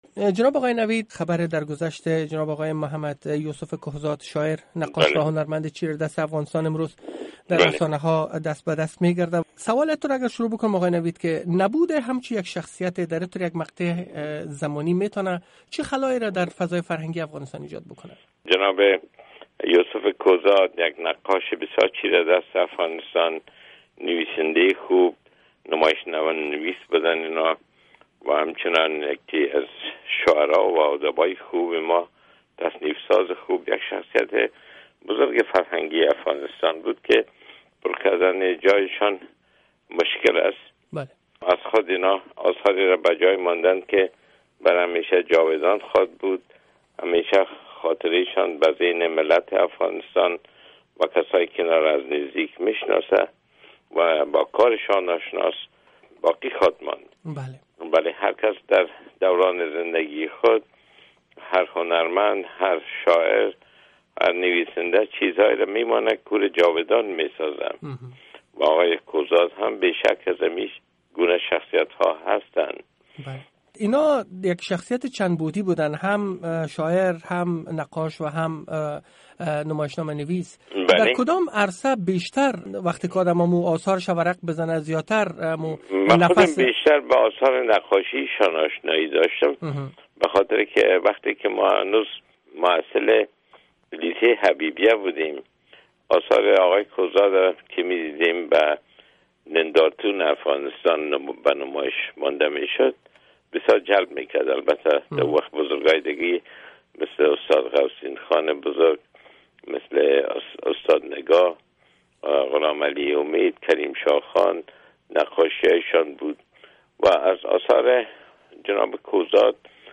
جریان مکمل مصاحبه